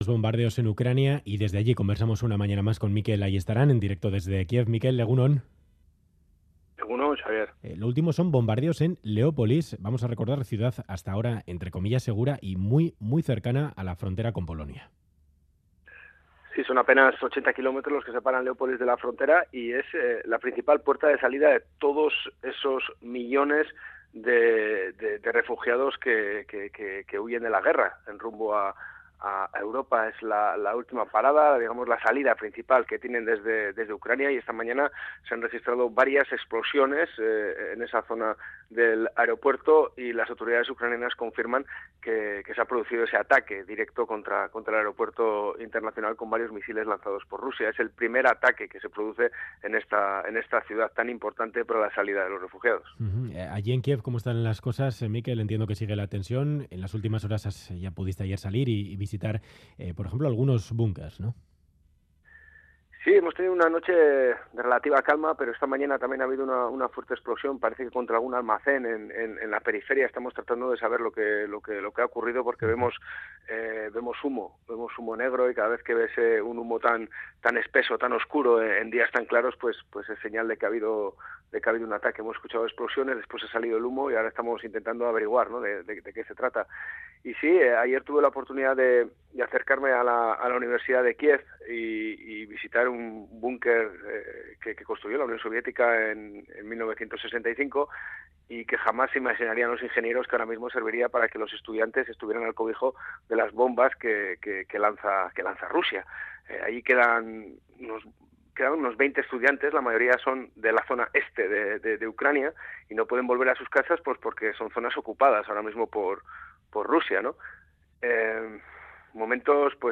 Crónica
desde Kiev.